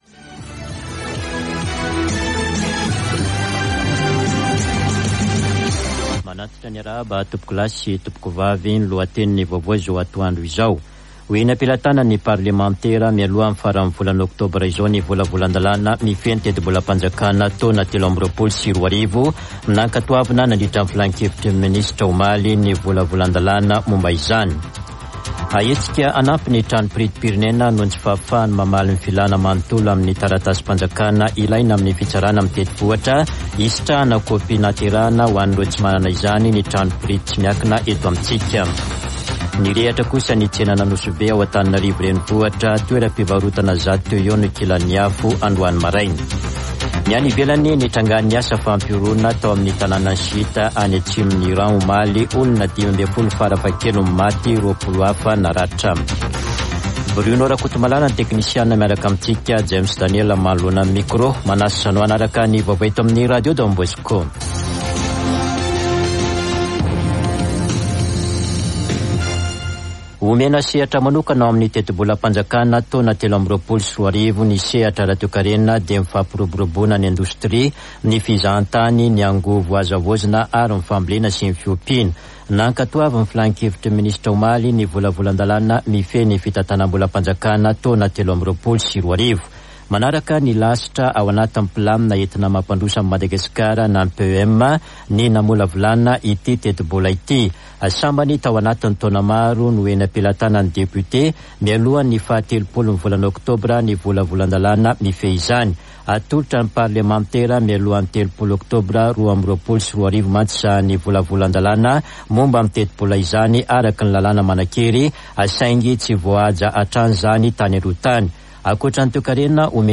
[Vaovao antoandro] Alakamisy 27 ôktôbra 2022